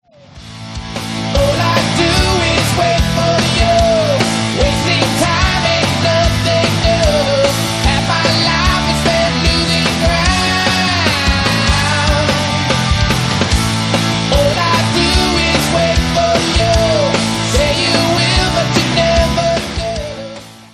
Vocals & Percussion
Guitar & Vocals
Guitar & Synthesizer
Fretless Bass
Drums